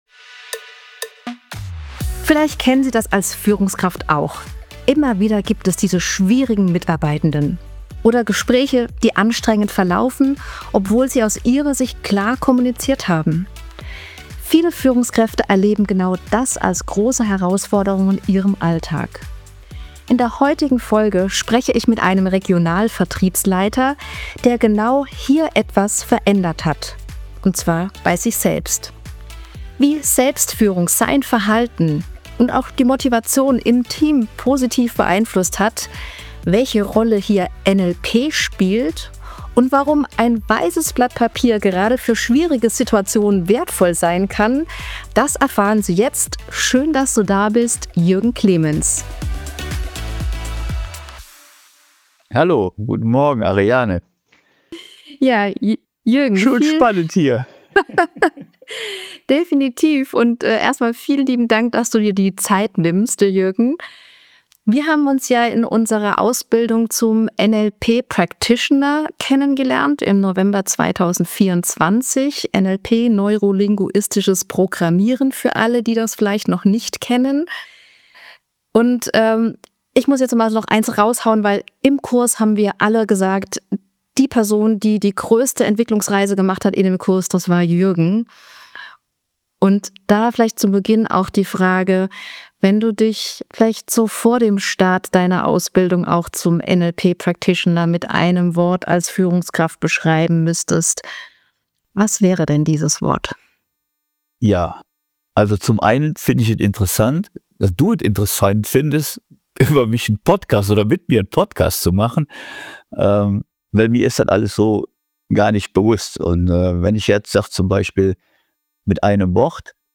In einem inspirierenden Gespräch berichtet er offen über seine persönliche Entwicklungsreise – von einem autoritätsgeprägten Führungsstil hin zu einer modernen, werteorientierten Haltung.